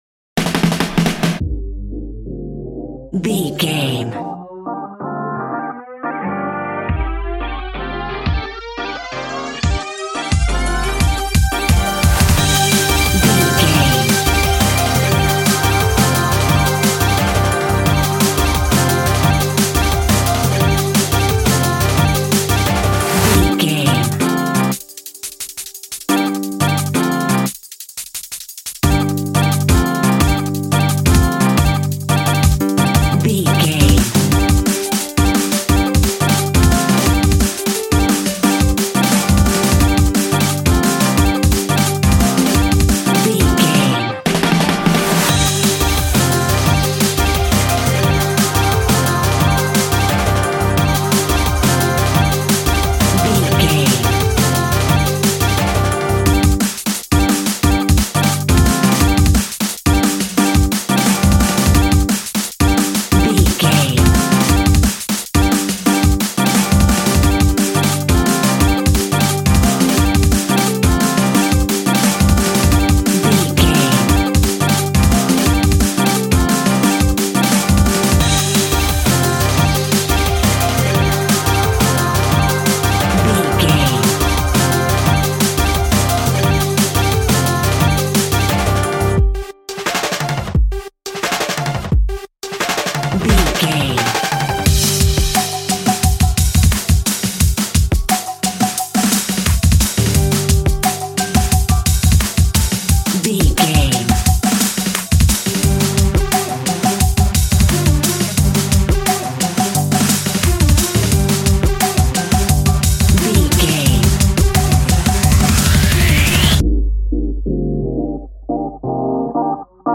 Drum and Bass Island.
Epic / Action
Fast paced
Aeolian/Minor
aggressive
powerful
dark
groovy
uplifting
futuristic
driving
energetic
drum machine
synthesiser
drums
break beat
electronic
sub bass
synth leads
synth bass